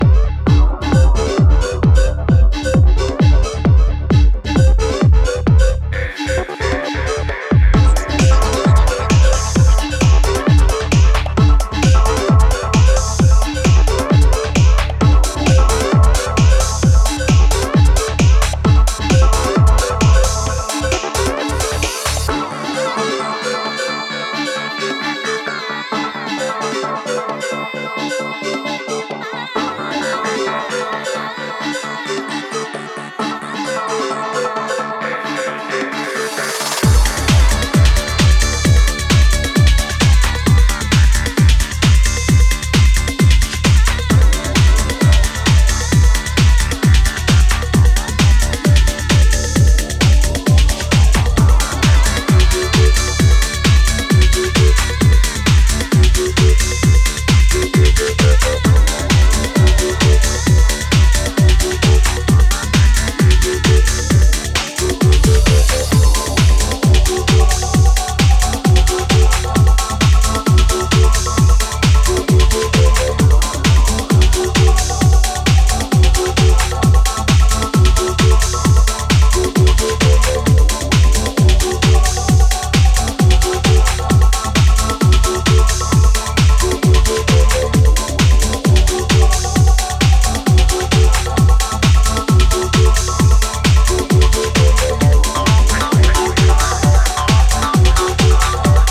plenty of raw energy